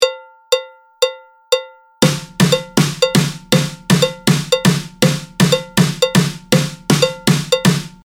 参考までにこれが付点８分の音符です。
BPM120
カウベルの音が１、２、３、４の4分音符
スネアの音が付点８分音符です。